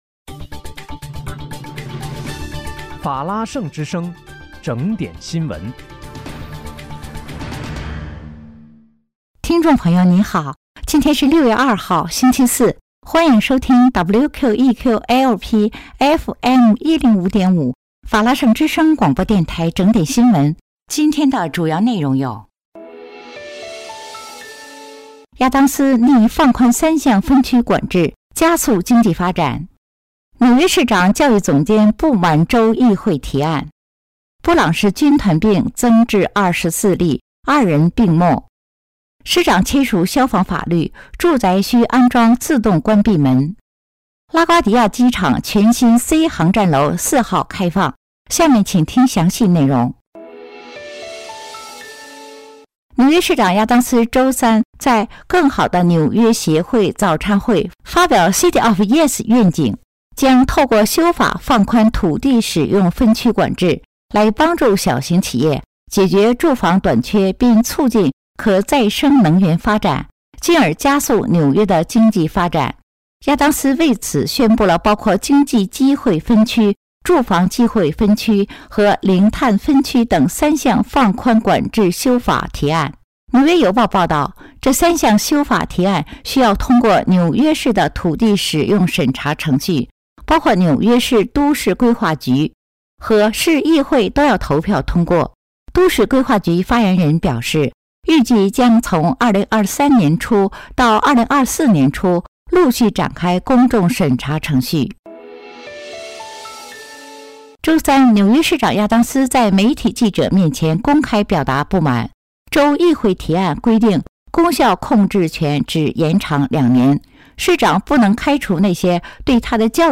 6月2日（星期四）纽约整点新闻